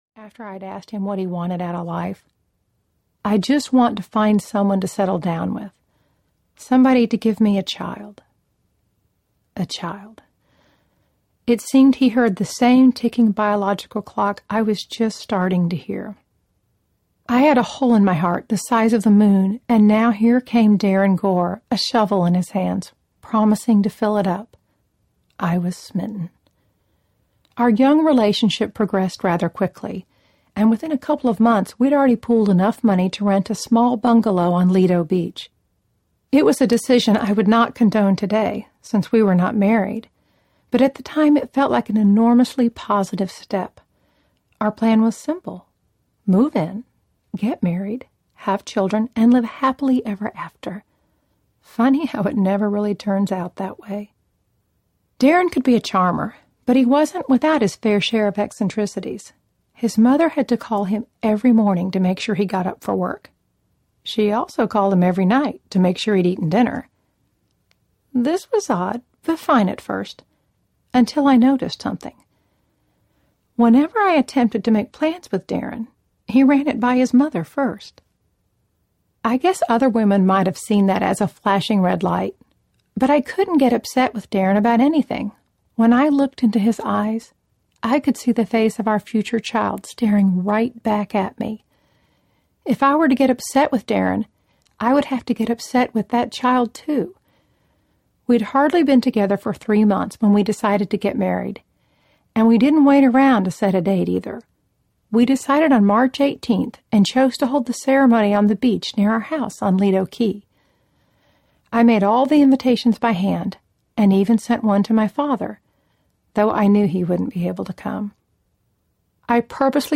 The Plain Choice Audiobook
Narrator
5.75 Hrs. – Unabridged